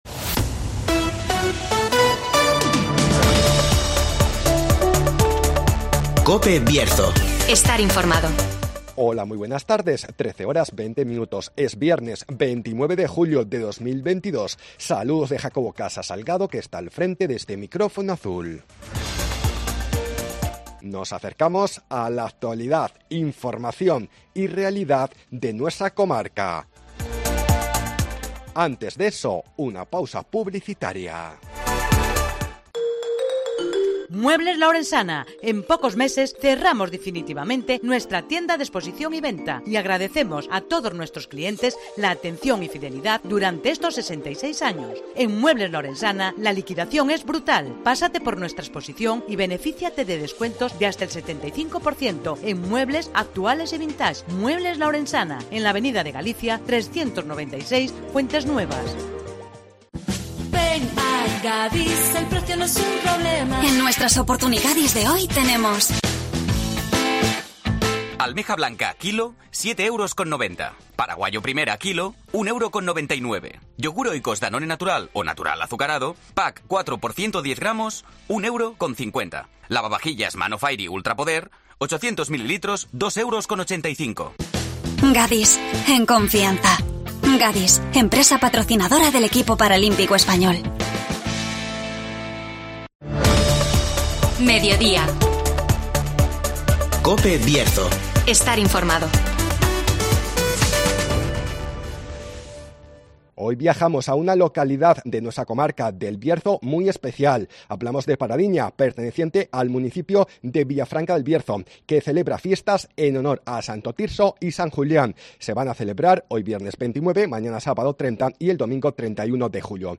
Fiestas en Paradiña en honor a Santo Tirso y San Julián (Entrevista